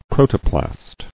[pro·to·plast]